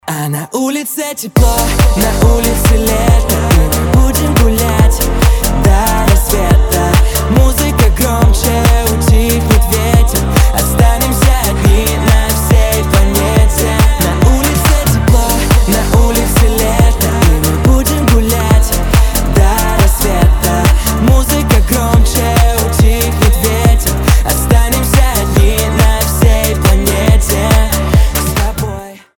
• Качество: 320, Stereo
позитивные